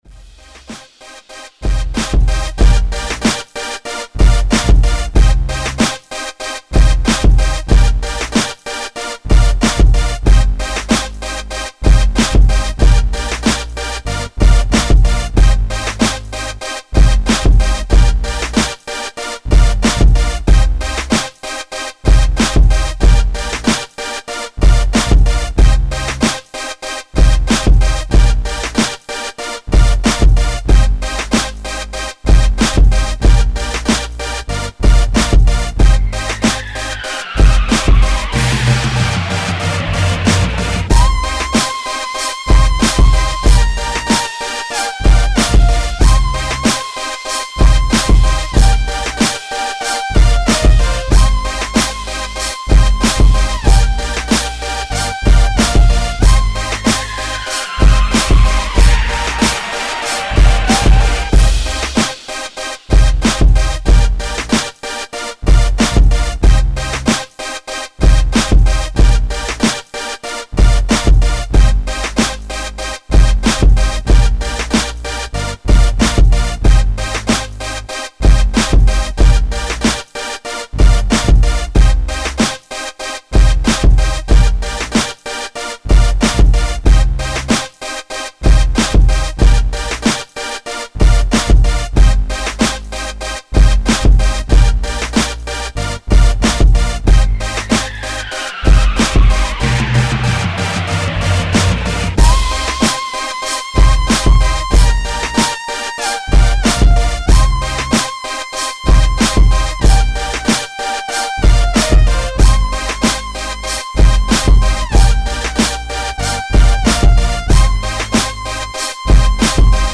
beaty